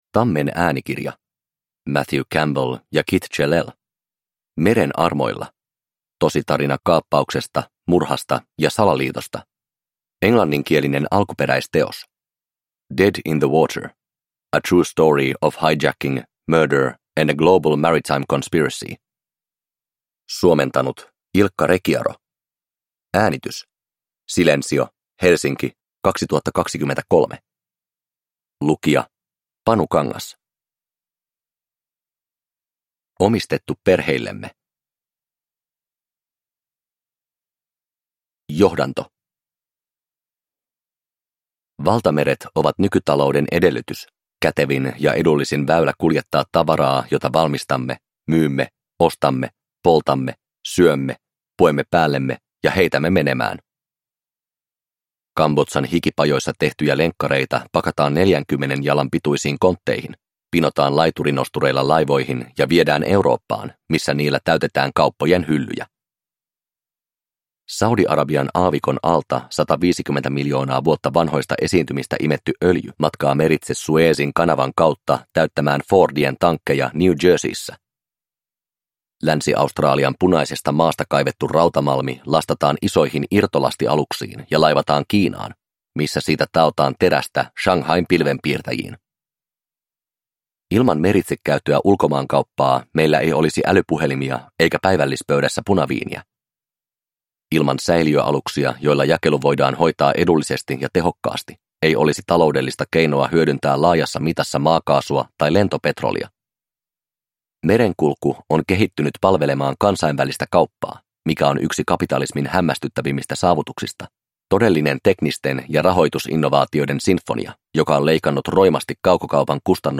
Meren armoilla – Ljudbok – Laddas ner